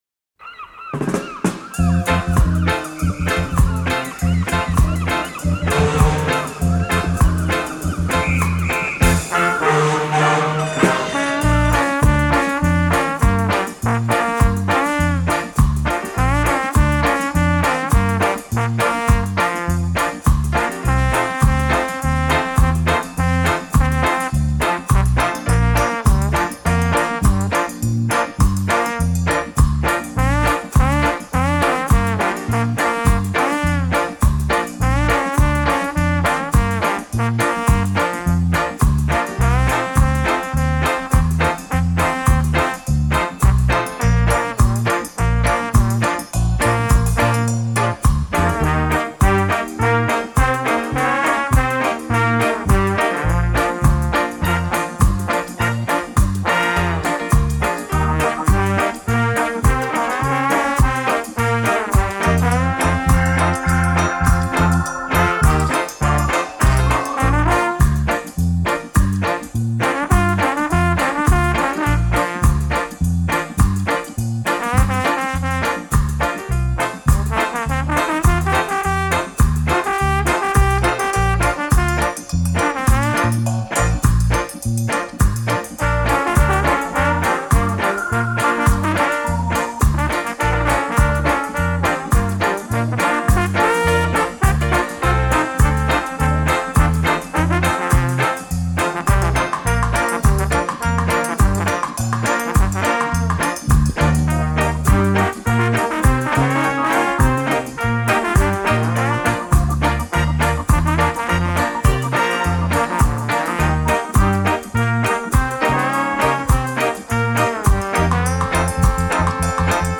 trombonist